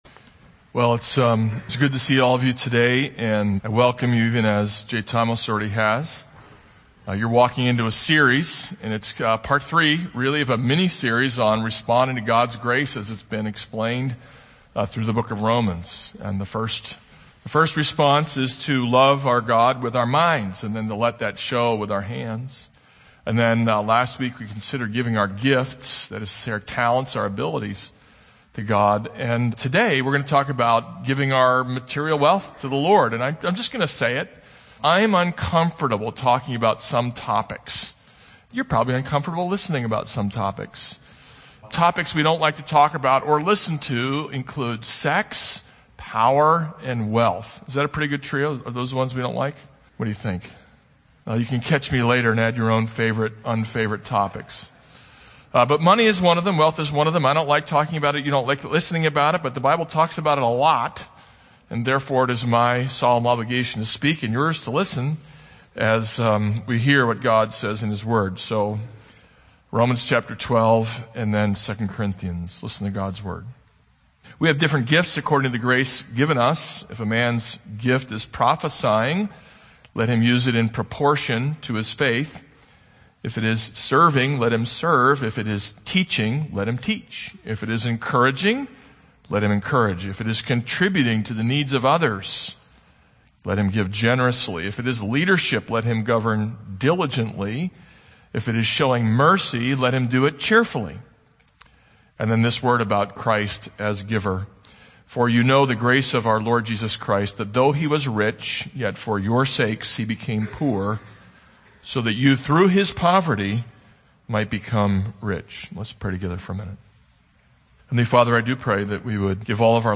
This is a sermon on Romans 12:6-8.